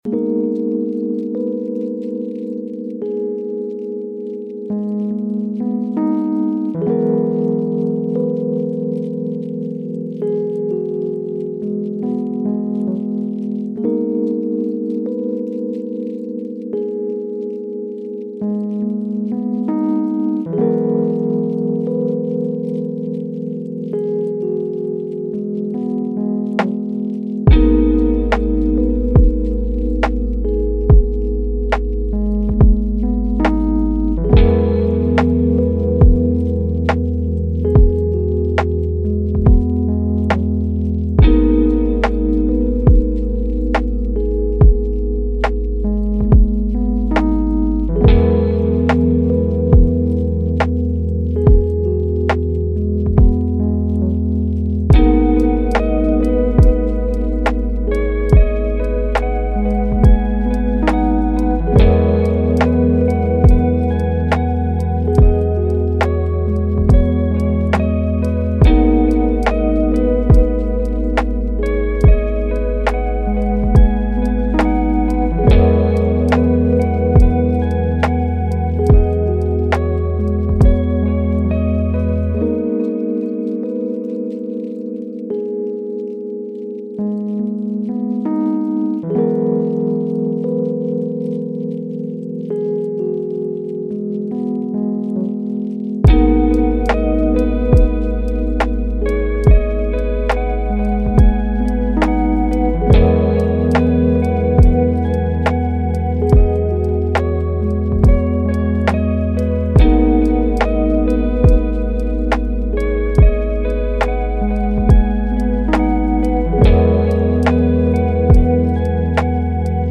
Mozart Violon : Étude Sereine